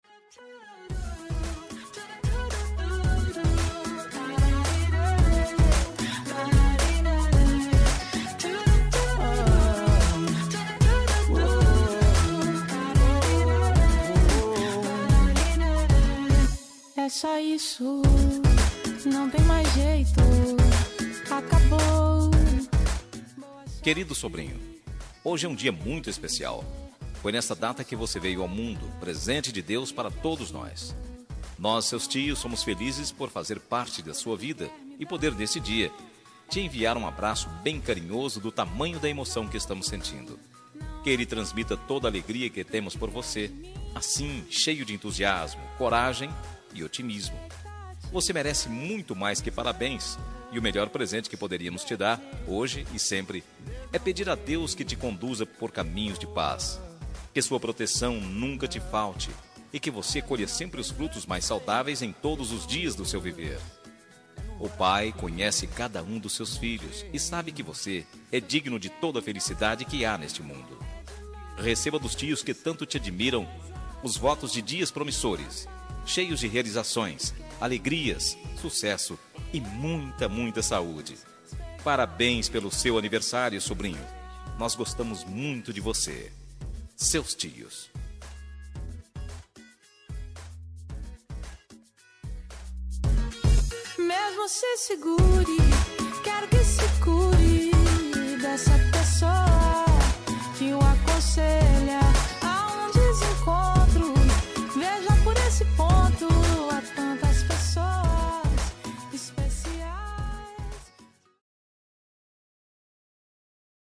Aniversário de Sobrinho – Voz Masculina – Cód: 2678 – Linda
2676-sobrinho-masc.m4a